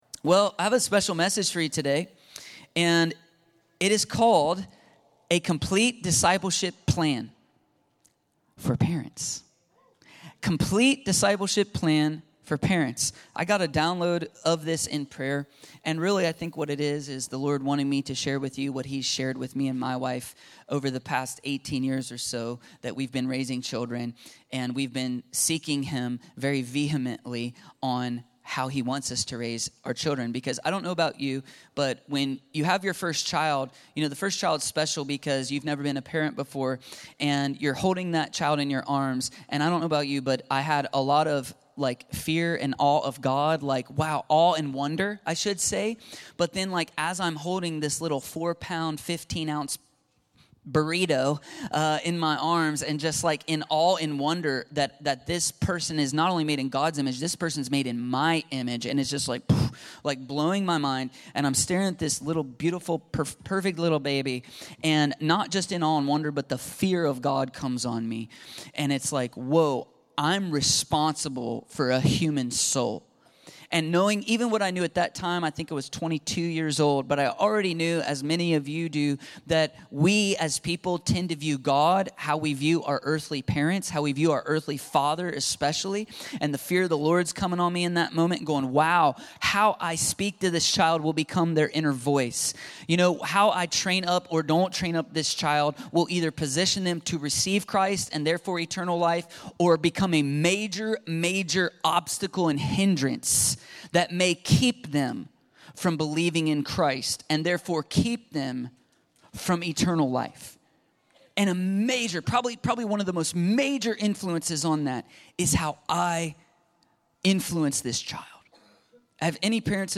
Complete Discipleship Plan for Parents - Complete Discipleship Plan for Parents ~ Free People Church: AUDIO Sermons Podcast